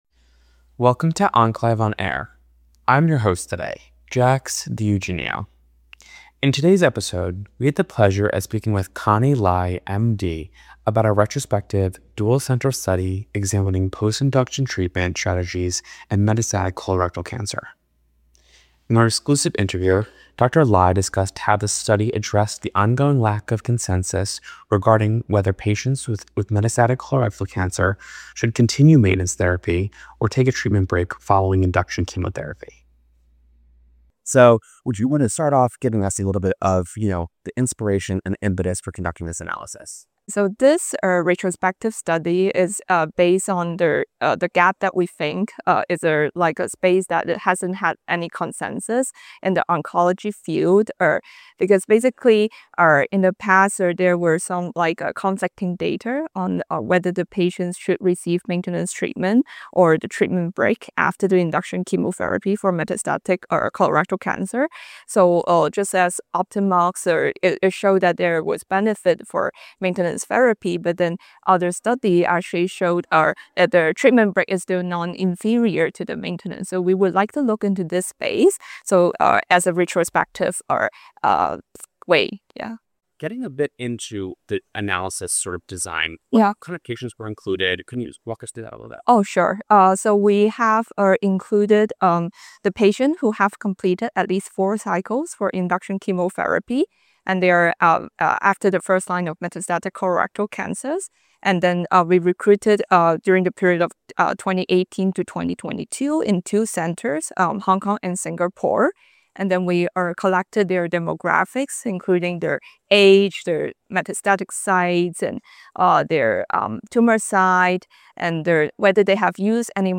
In OncLive® On Air, you can expect to hear interviews with academic oncologists on the thought-provoking oncology presentations they give at the OncLive® State of the Science Summits.